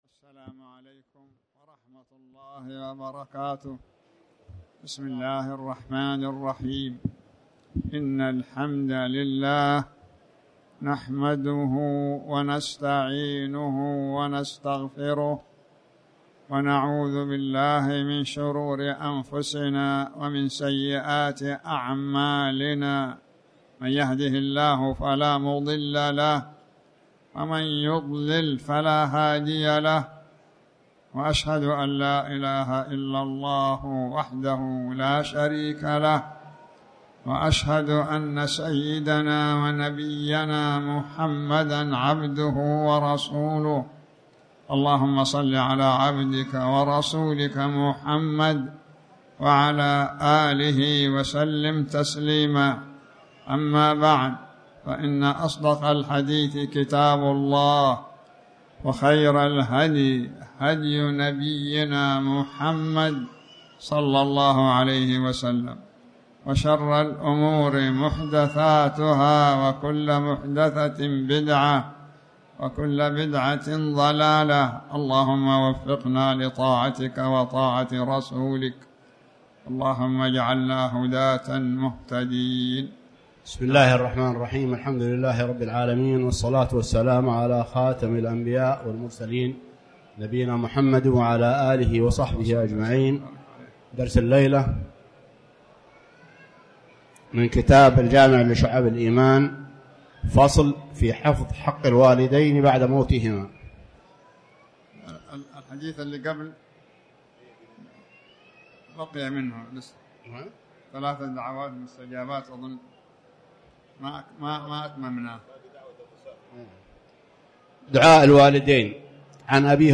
تاريخ النشر ٥ ربيع الأول ١٤٤٠ هـ المكان: المسجد الحرام الشيخ